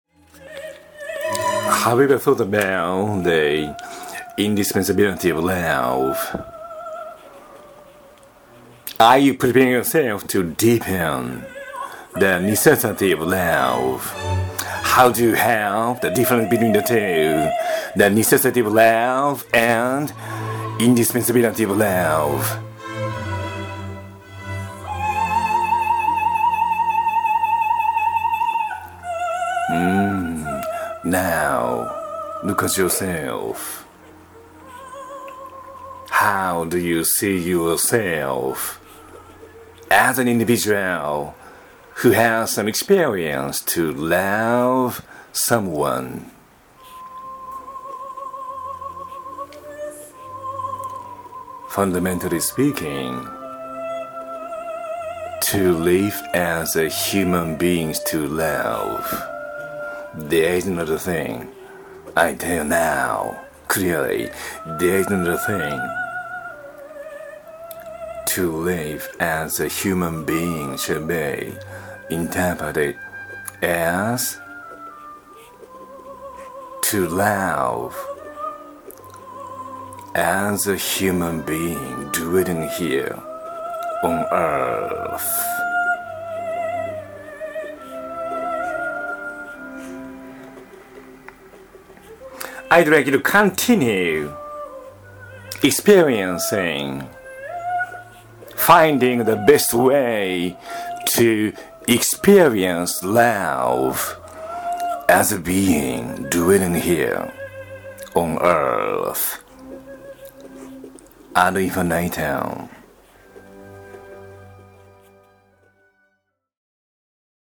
■Recitative-style lecture （”lecture dramatized” in the opera） ⇒ Destiny